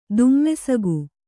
♪ dummesagu